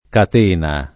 kat%na] s. f. — anche top. (C., la C.) o elem. di toponimi (Aci C., Sic.) — sim. il pers. f. C., usato in Sic., e il cogn. C. — con -e- chiusa, conforme all’-e- lunga del lat. catena, anche i der. (io) discateno, incateno, rincateno, scateno, compreso (benché di formaz. dòtta) concateno — cfr. La Catena